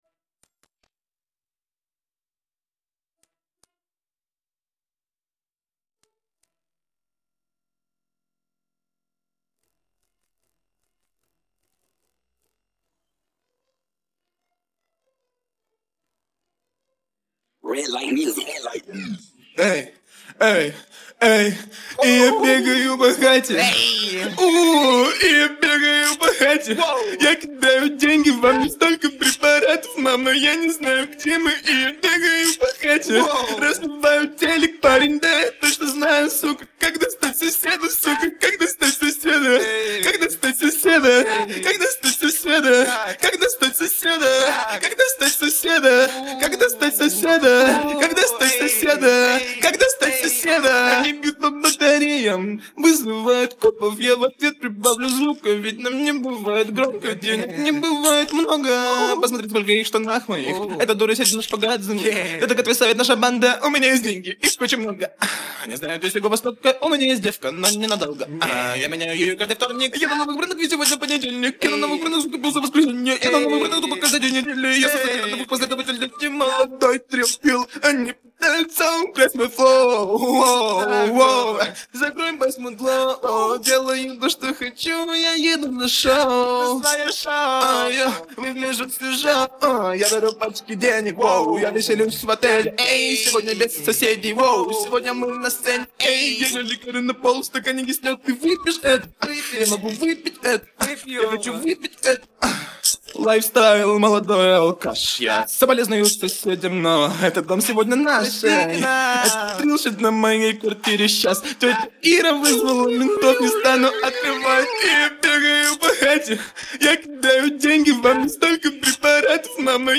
Lauluosuus